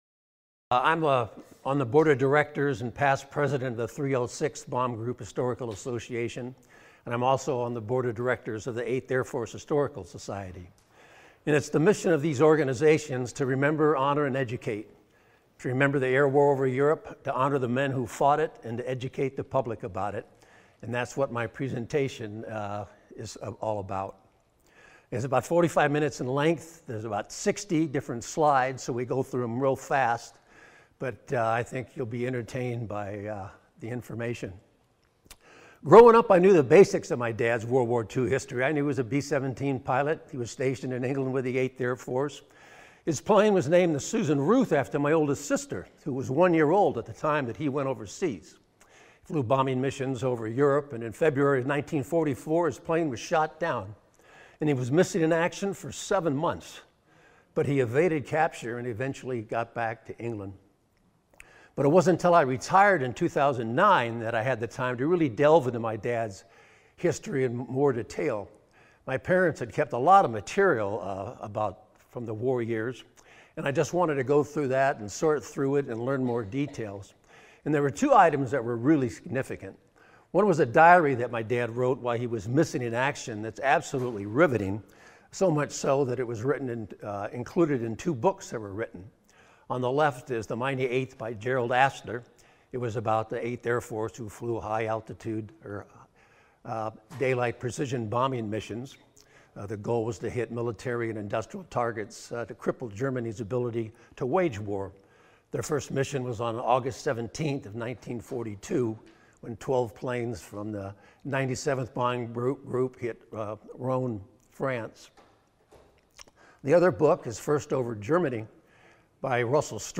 Presentation at the 15th Air Force Reunion